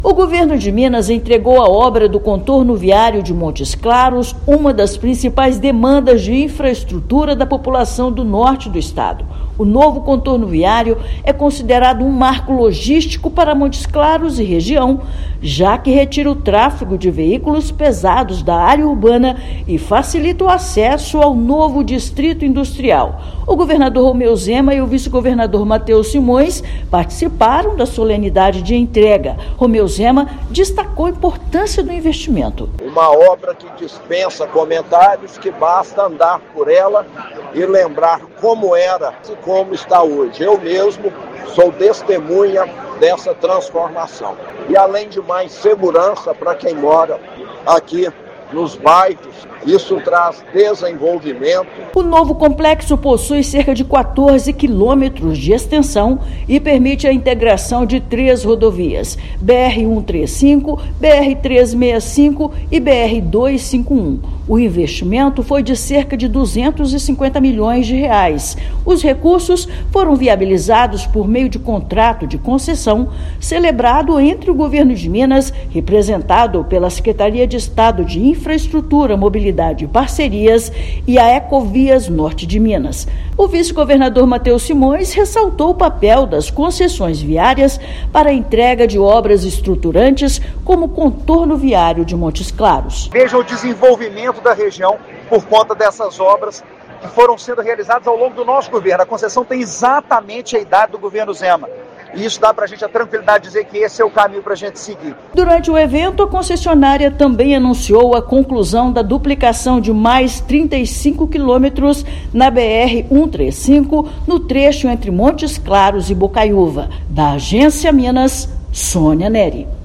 [RÁDIO] Governo de Minas entrega obra do Contorno Viário de Montes Claros
Com novo complexo, região se consolida ainda mais como ponto estratégico no cenário logístico nacional. Ouça matéria de rádio.